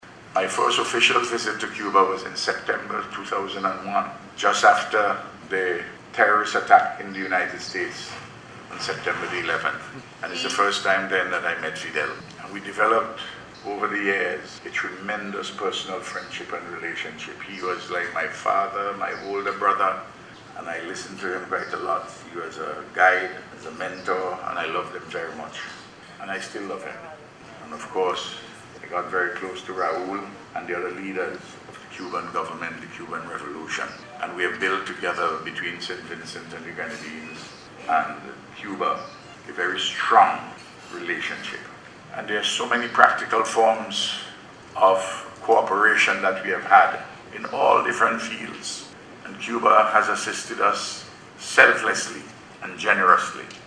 He made the statement during a political-cultural event held in Havana to celebrate thirty years of diplomatic ties between St. Vincent and the Grenadines and Cuba.